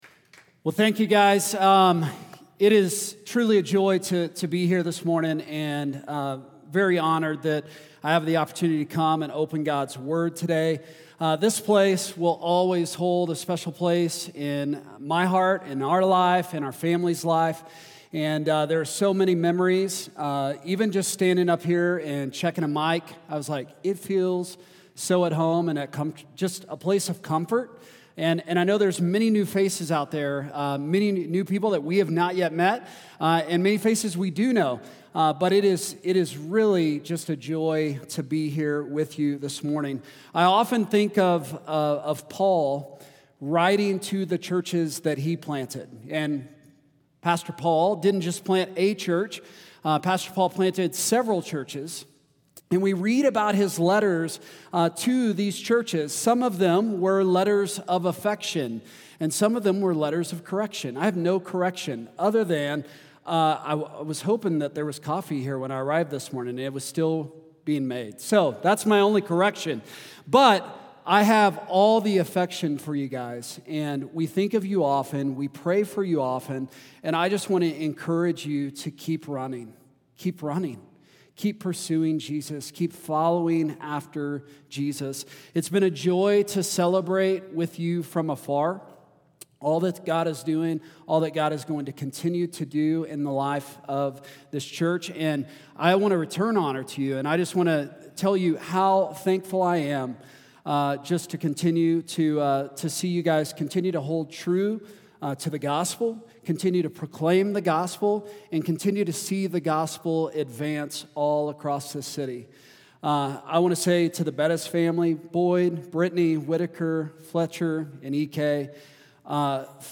preaches on Acts 3:11-26. Big idea: You need Jesus